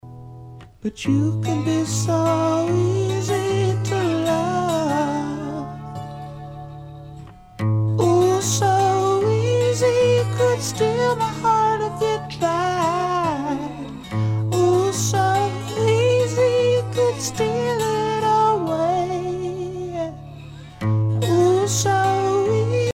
"dirty," in other words, with a burst of distortion often accompanying
For a quick MP3 clip of two sibilance examples, please go to: